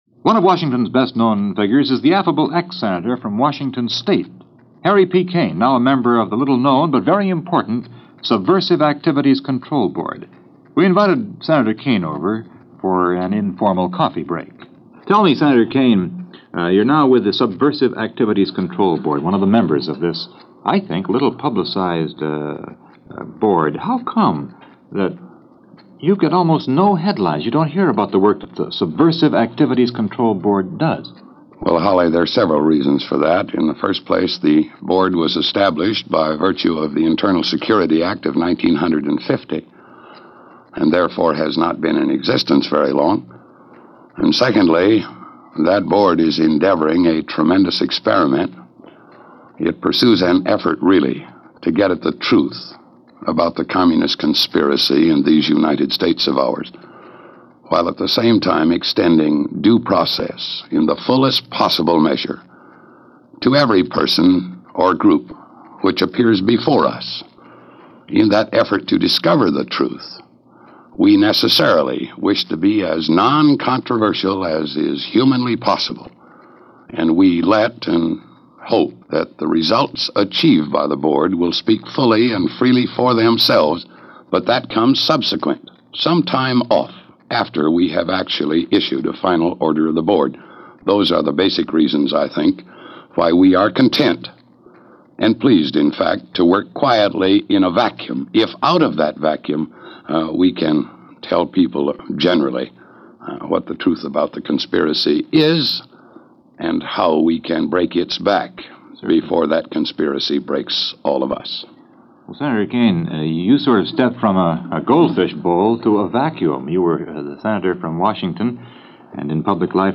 This interview is from the weekly radio program Listen To Washington from 1954.